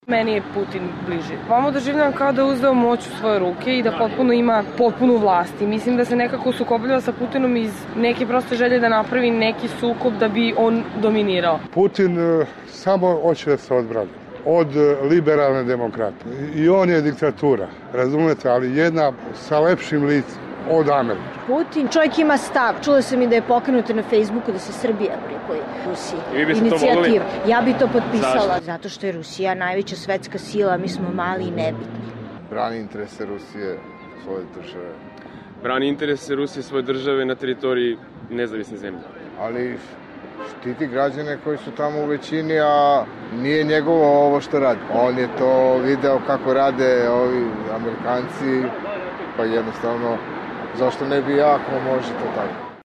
Iako se u Srbiji i dalje uporno negira postojanje kosovske države, koja se posle ratnih sukoba i NATO intervencije na tadašnju SRJ 2008. zvanično osamostalila, čak ni Putinova analogija Kosova i Krima, građane sa kojima smo razgovarali nije pokolebala u privrženosti ruskom lideru.